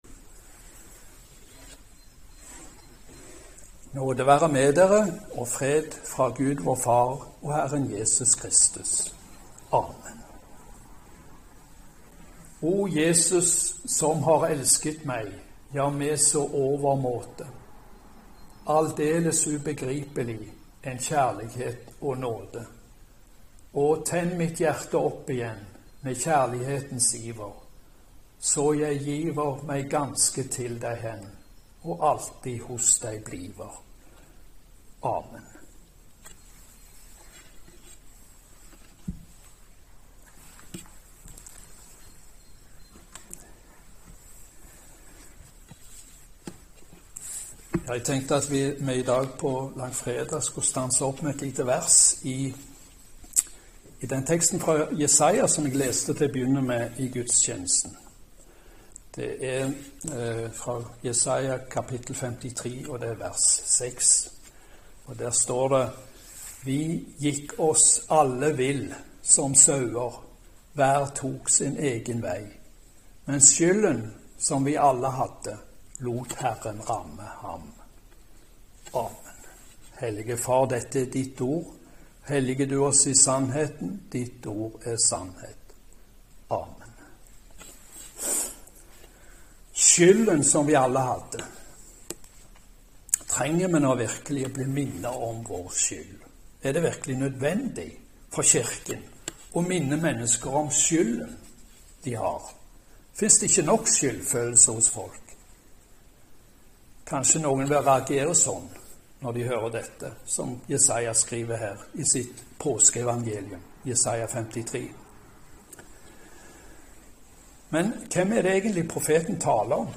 Langfredagspreken